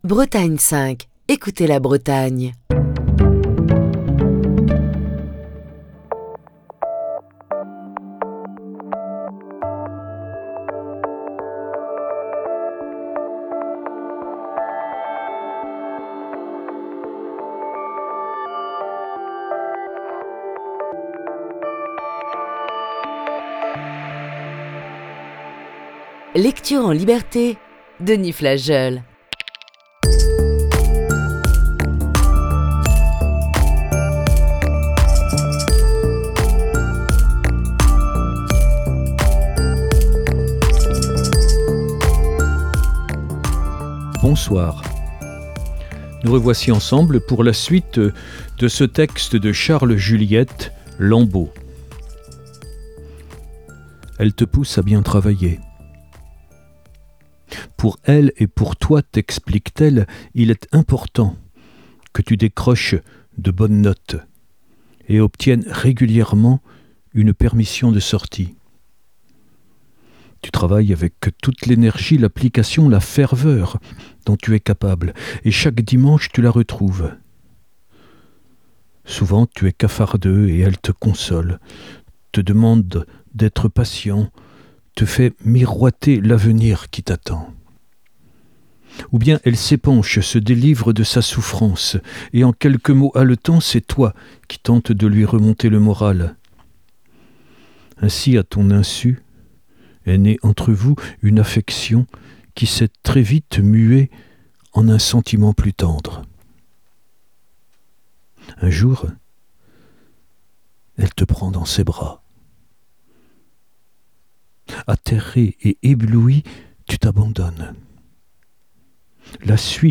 la lecture du roman